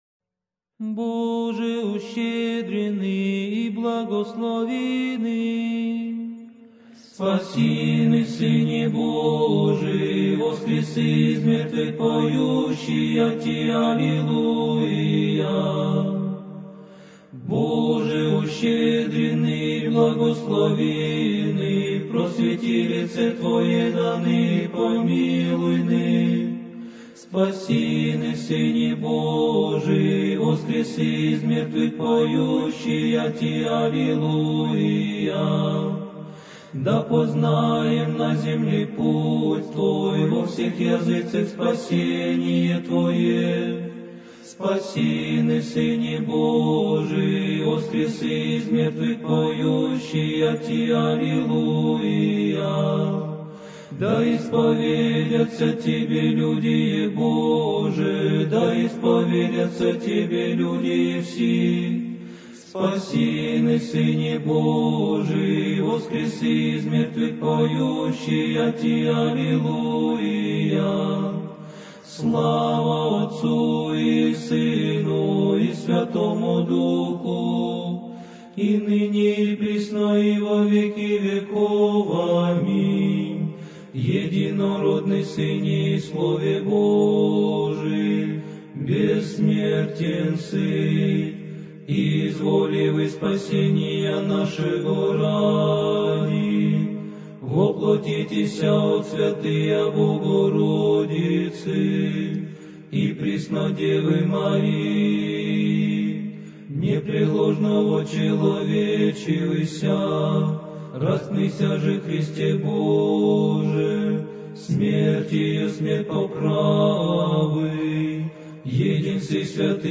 Христианская музыка